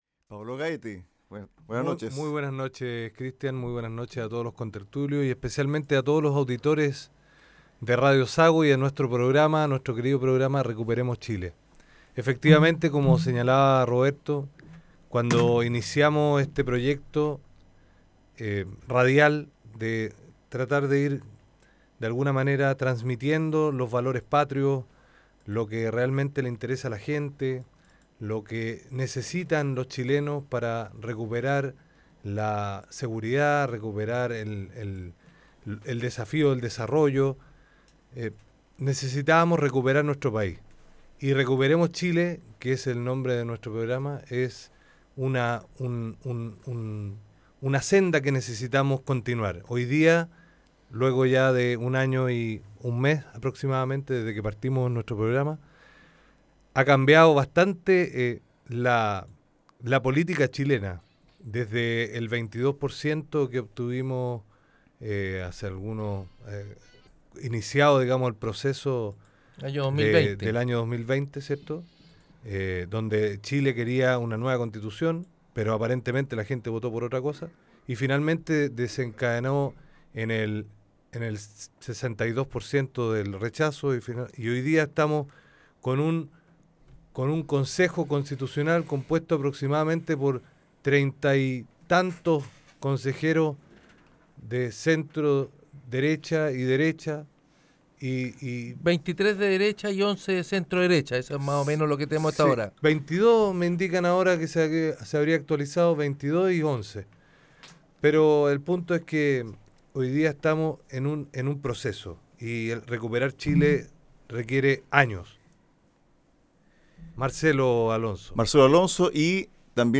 En este capítulo los panelistas analizan el abrumador triunfo del Partido Republicano en la elección de consejeros constitucionales, donde obtuvo 23 escaños, siendo la fuerza política más importante del Consejo y la más votada de la historia política del país. También, los integrantes del programa abordan los alcances de esta nueva derrota política del Gobierno de Boric, como asimismo el reordenamiento de fuerzas tanto en el oposición como en el oficialismo.